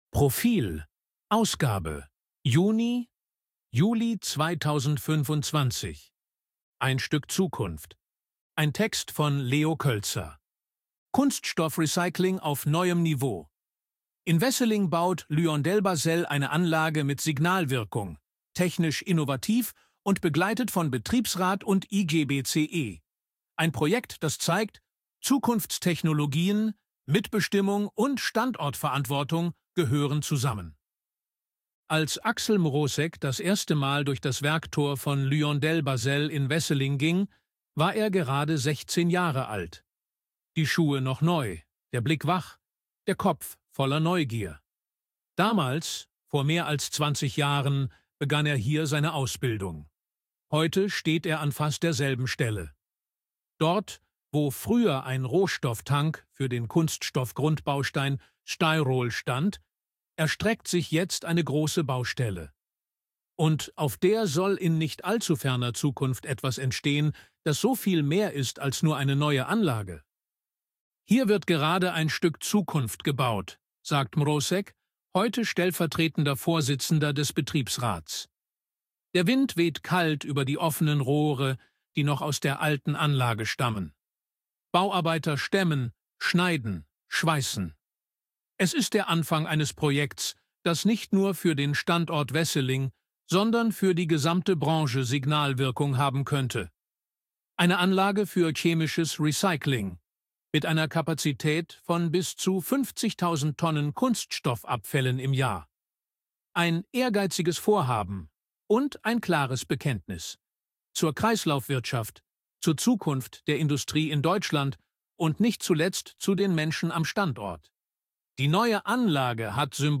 Artikel von KI vorlesen lassen ▶ Audio abspielen
ElevenLabs_KI_Stimme_Mann_Reportage.ogg